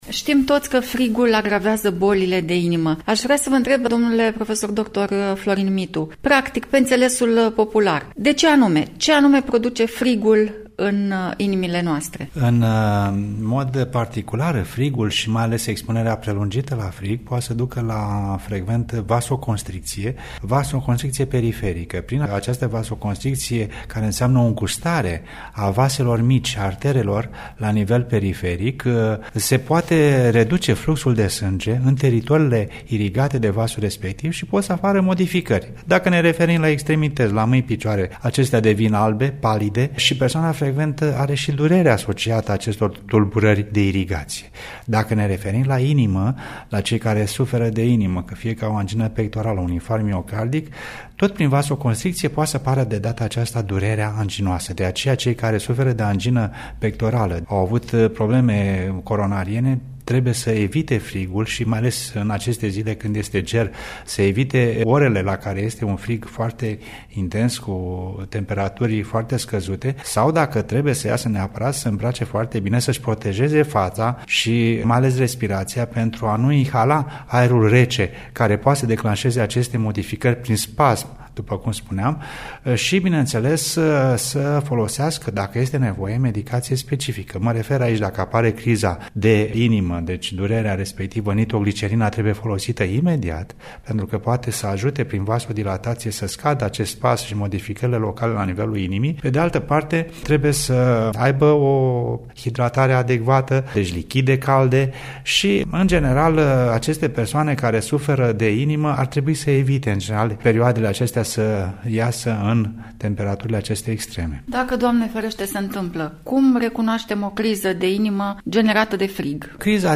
Un interviu